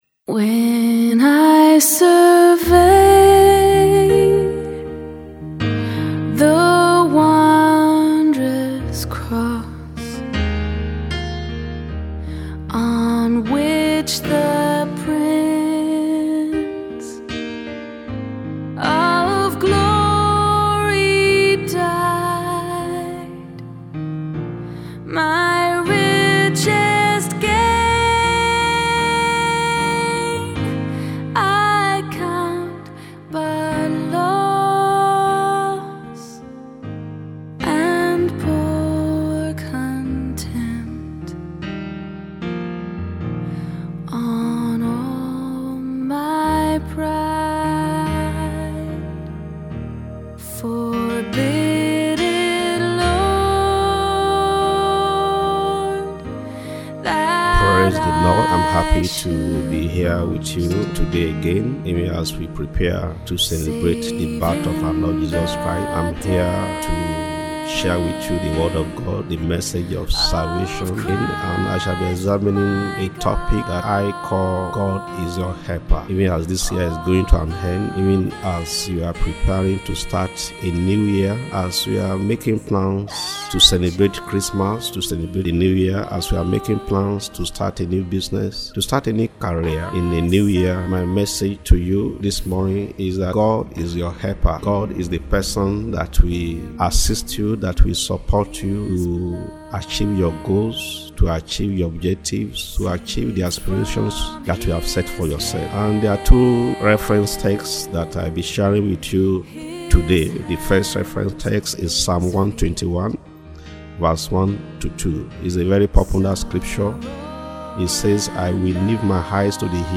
Audio sermon: God is your helper - Save the World Ministry